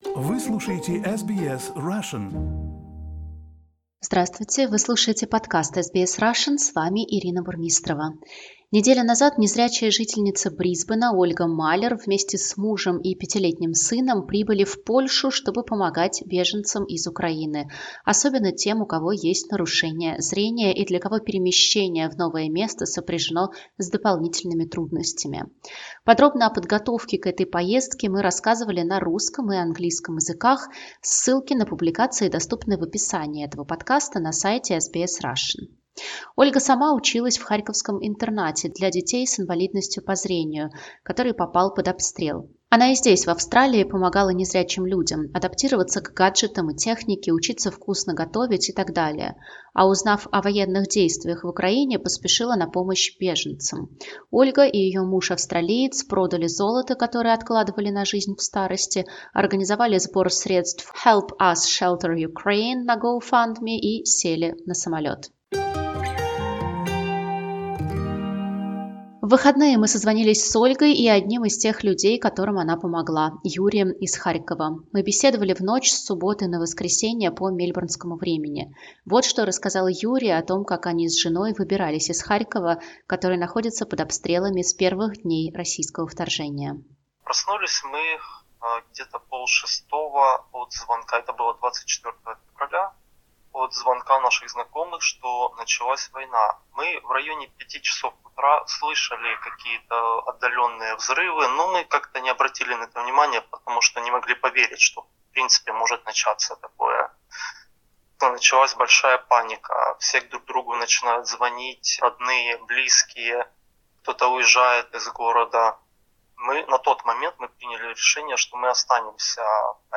Мы беседовали в ночь с субботы на воскресенье по мельбурнскому времени.